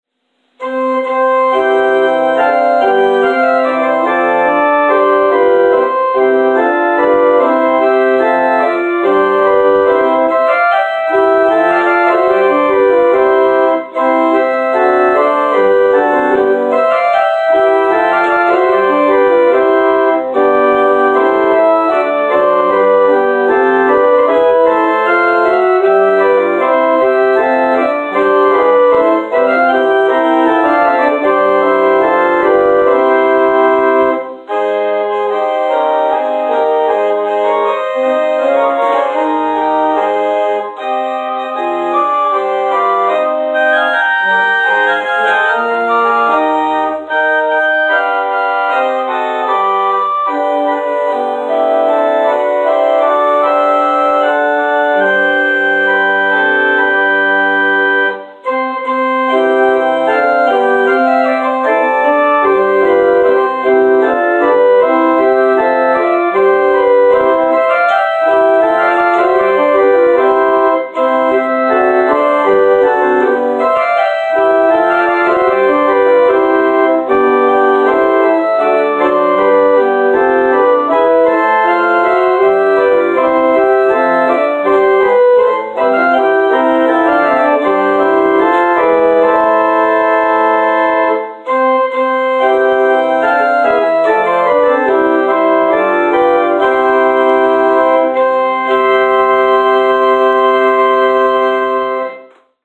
Postlude: “Marche Triomphale” – Randolph Johnston